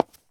footstep_concrete_walk_01.wav